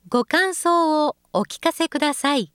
ホームページ作成で利用できる、さまざまな文章や単語を、プロナレーターがナレーション録音しています。
052-gokansouwookikasekudasai.mp3